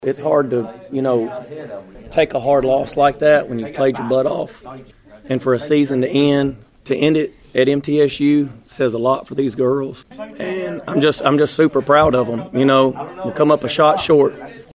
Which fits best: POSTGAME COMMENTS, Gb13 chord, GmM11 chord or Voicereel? POSTGAME COMMENTS